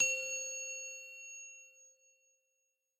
simple-bell.mp3